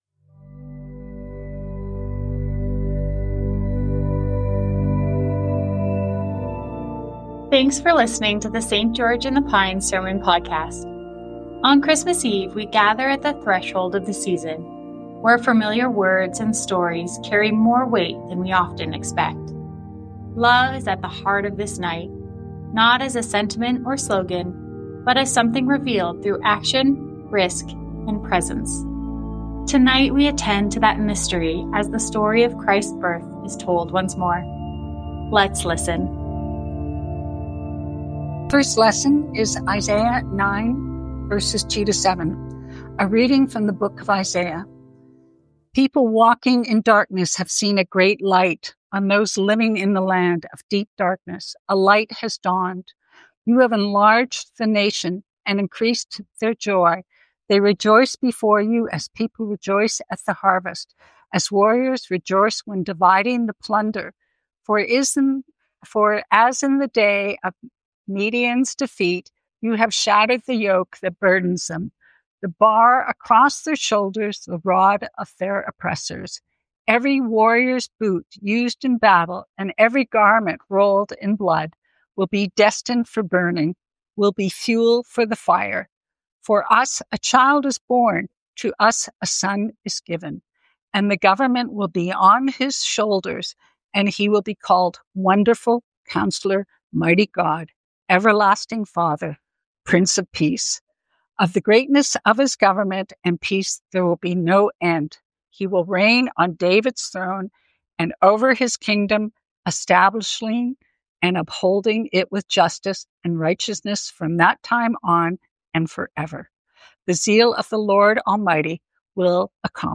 Christmas Eve homily